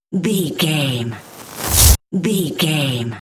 Trailer raiser
Sound Effects
Fast paced
In-crescendo
Atonal
futuristic
intense
riser